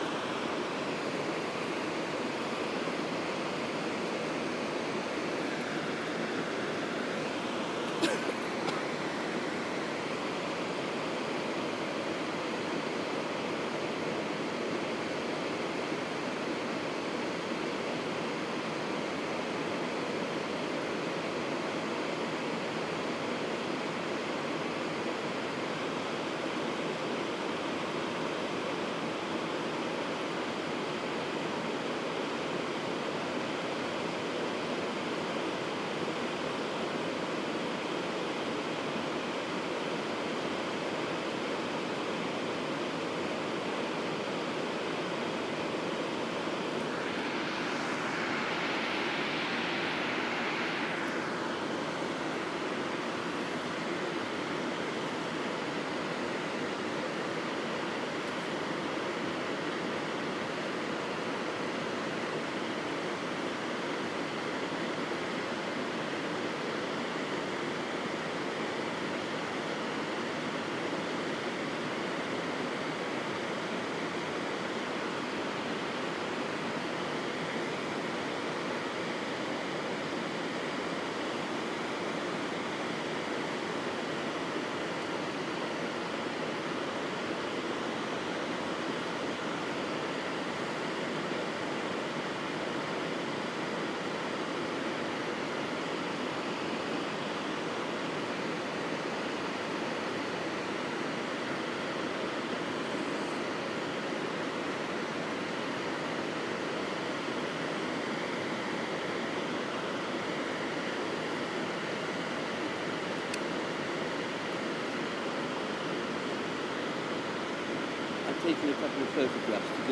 Weir sound then Organ of Corti discussion